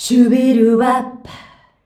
SHUBIWAP C.wav